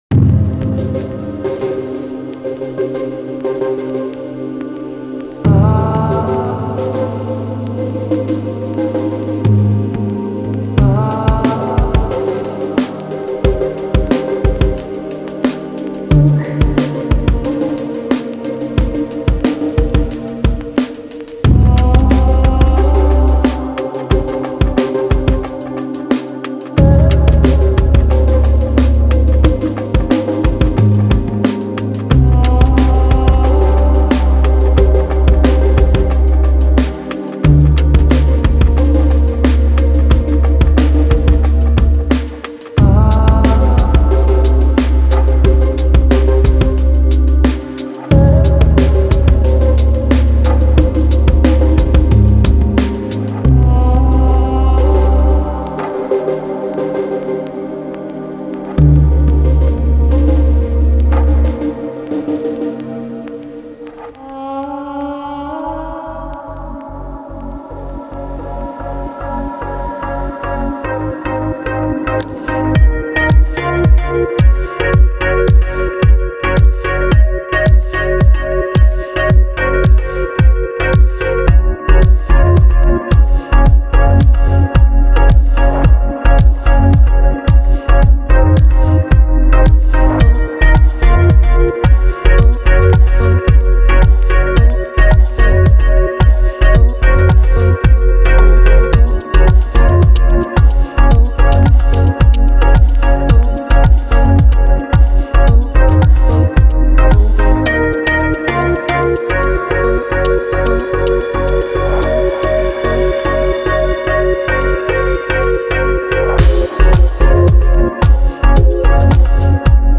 是您在曲目中营造深沉，冰爽和非凡氛围的解决方案。
BPM在70到110之间。
我们确保您会得到郁郁葱葱的合成器，旋律，弹奏，sfx和打击垫声音以及大量不同的鼓工具的完美结合。
• 8 Vinyl Crackles
• 12 Drum Breaks
• 37 Melodic Loops